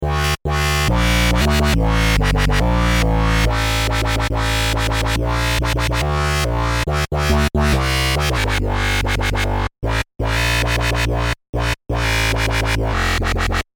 标签： 140 bpm Dubstep Loops Synth Loops 2.31 MB wav Key : Unknown
声道立体声